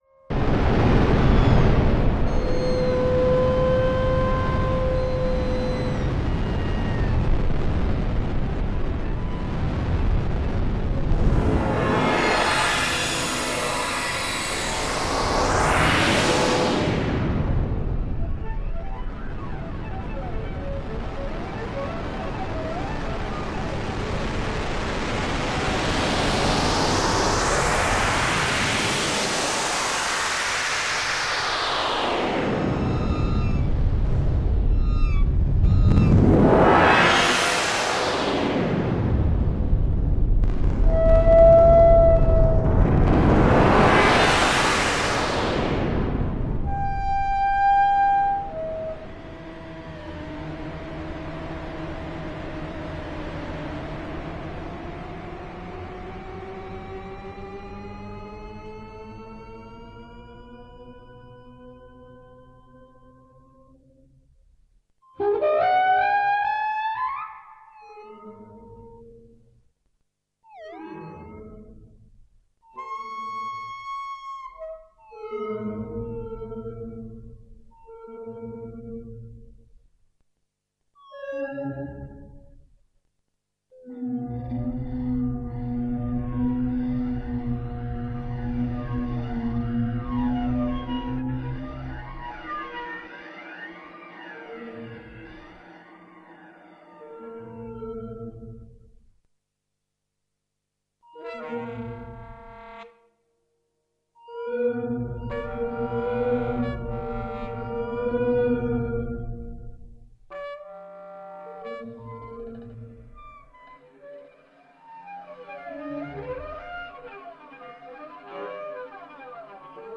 piano, clavichord, zither, electronics